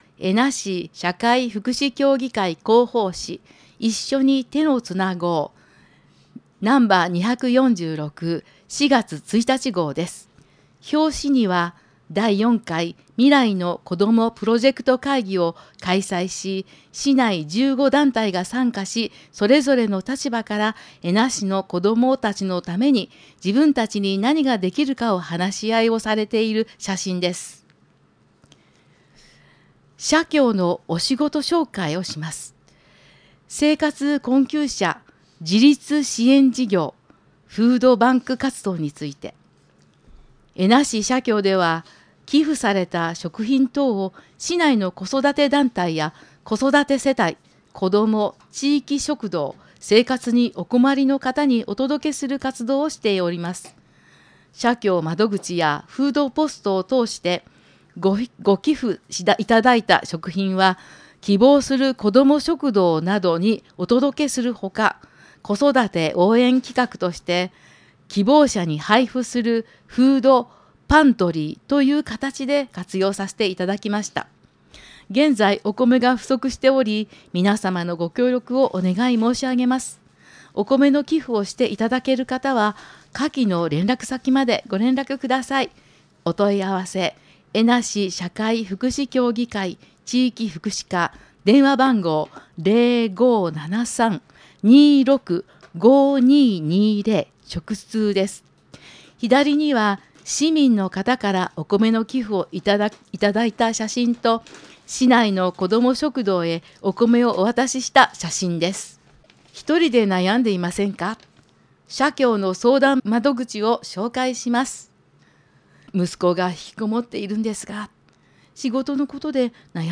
広報紙音声版はこちら↓
音声版は記事を抜粋してお届けしております。